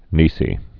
(nēsē, nā-)